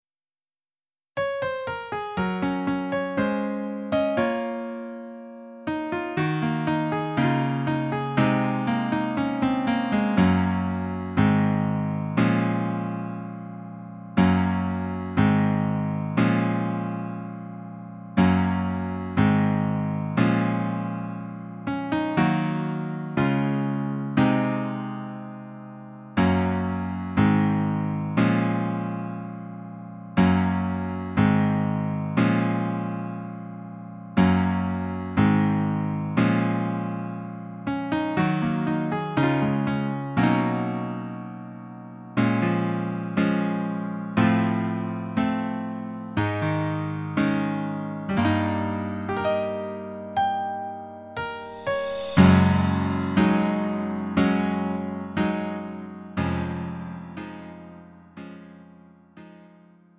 음정 -1키 4:00
장르 구분 Lite MR